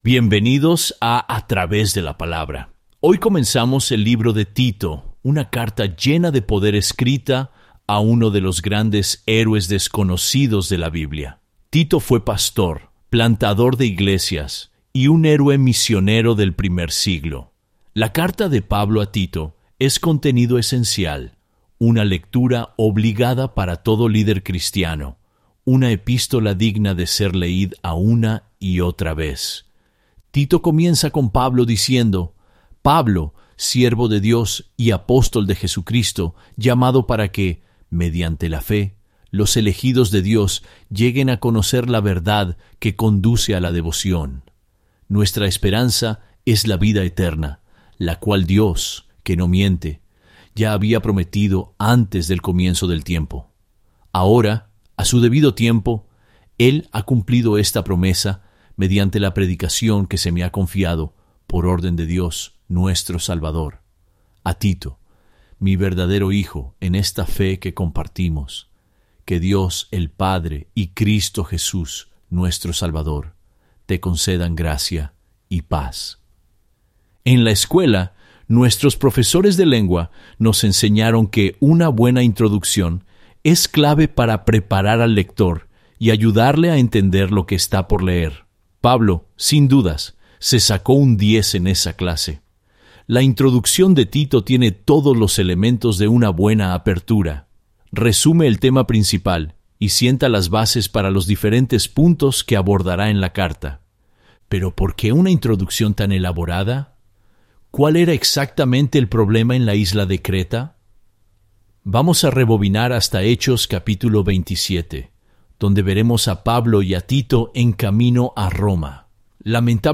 Para el beneficio de nuestros oyentes, TTW Bible Audio Guides (Guías de audio de “A Través de la Palabra”) utiliza tecnologías de IA para recrear las voces de los maestros en diferentes idiomas, con el apoyo de la supervisión humana y la garantía de calidad.